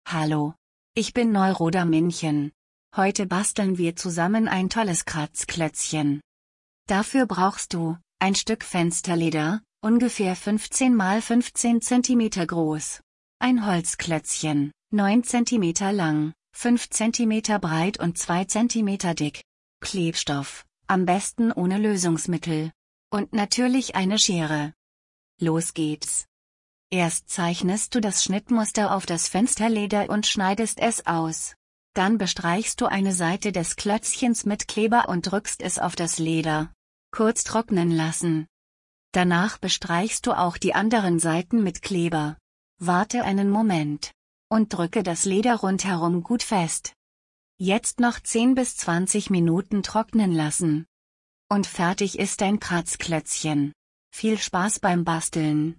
Hier wird die Anleitung vorgelesen